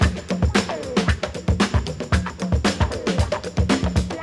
• 114 Bpm High Quality Drum Groove D Key.wav
Free breakbeat sample - kick tuned to the D note. Loudest frequency: 1086Hz
114-bpm-high-quality-drum-groove-d-key-3Ja.wav